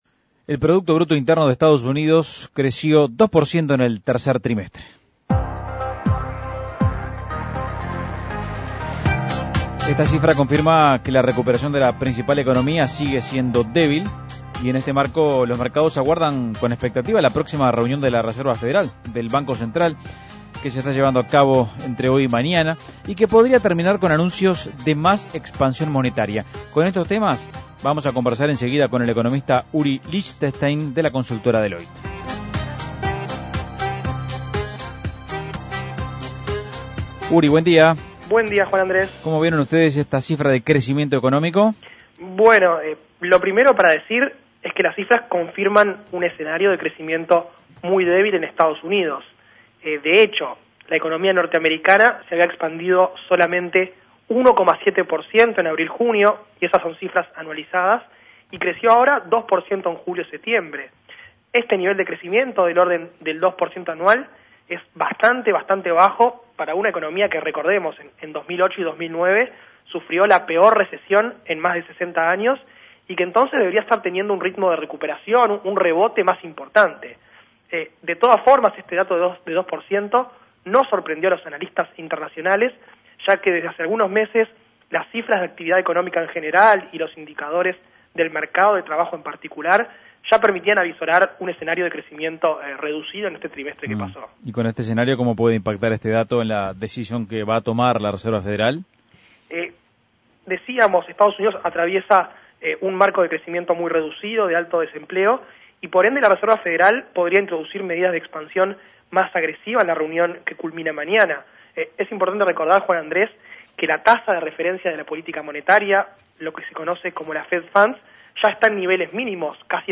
Análisis Económico ¿Qué mostraron los últimos datos de actividad económica en Estados Unidos?